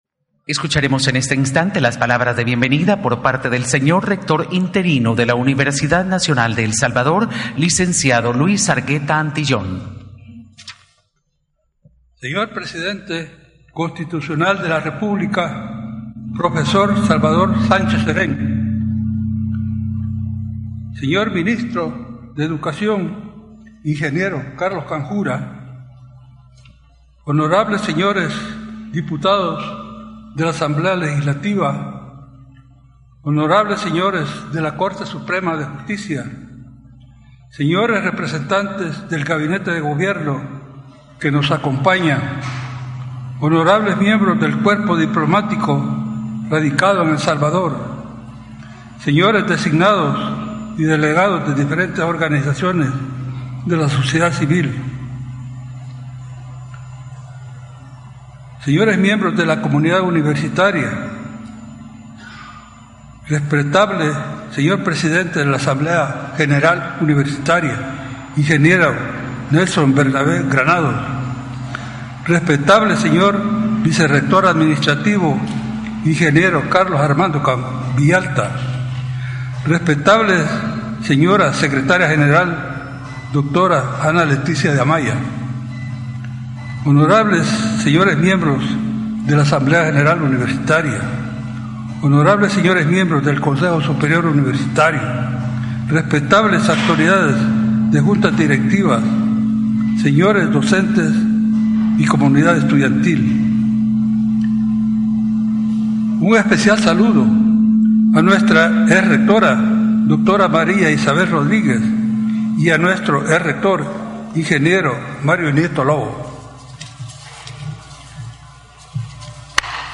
El Presidente de la República Salvador Sanchéz Cerén junto al Rector de la UES Luis Argueta Antillón y el Ministro de Educación Carlos Canjura realizaron el lanzamiento de la Universidad en línea